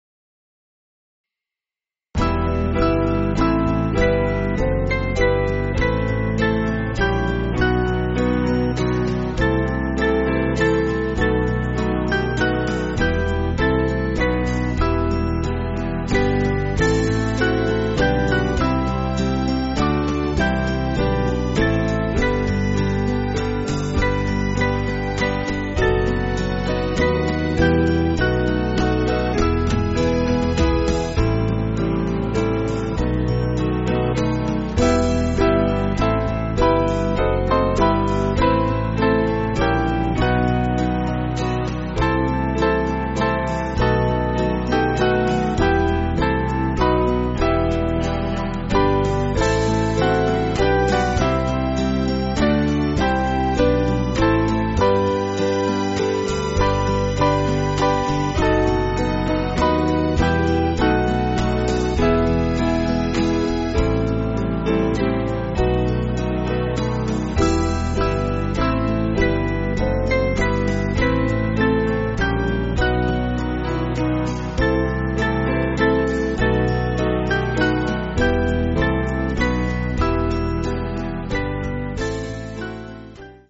Small Band
(CM)   4/Em